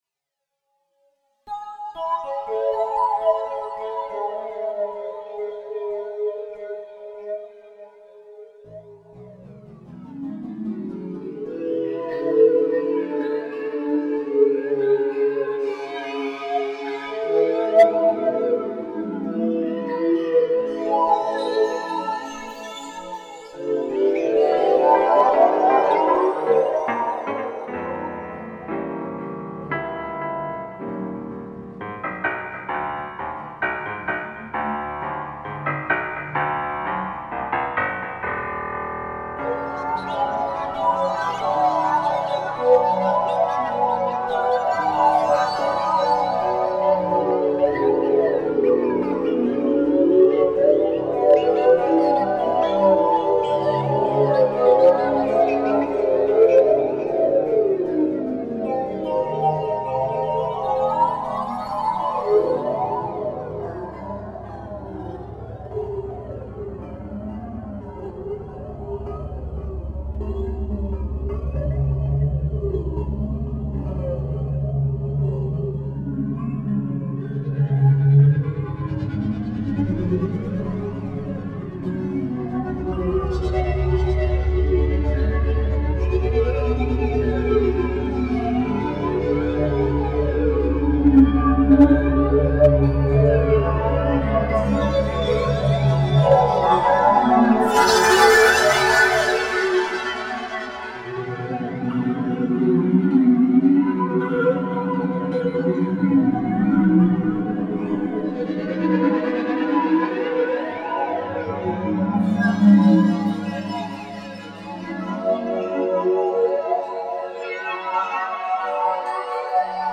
The outline for the music to the one-act ballet.